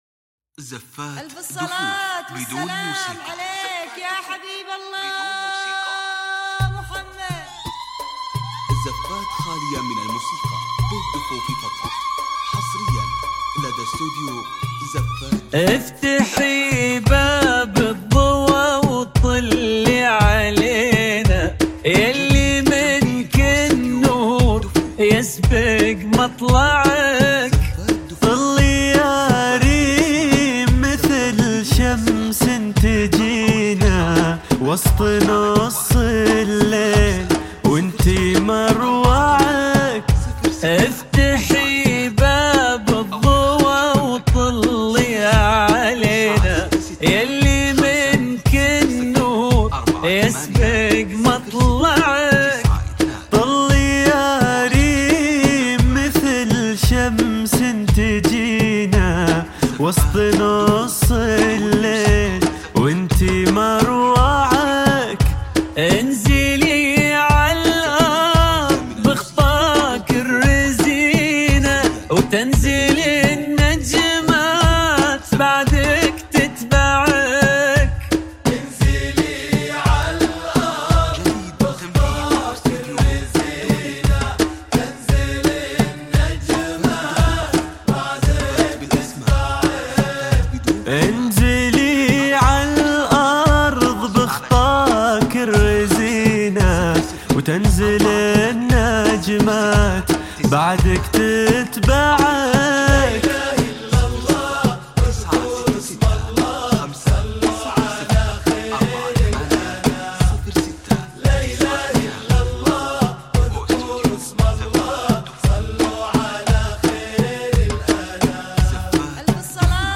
بدون موسيقى